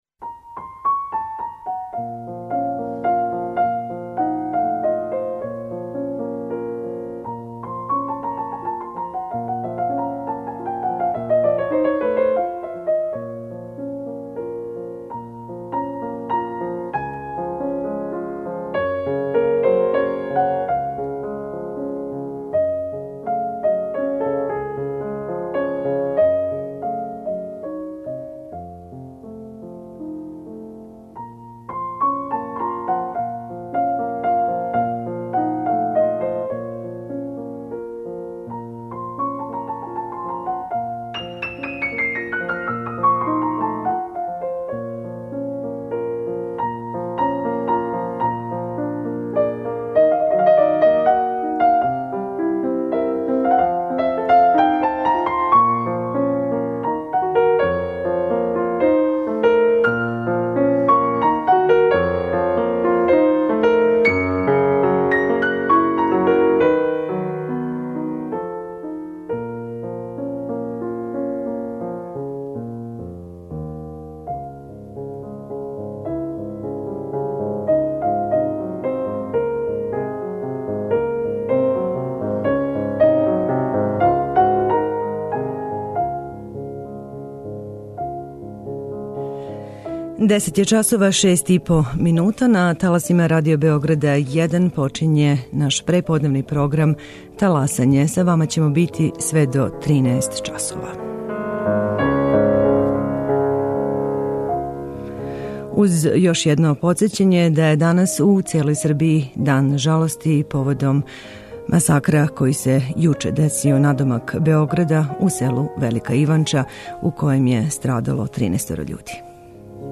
Она ће бити наша госшћа телефоном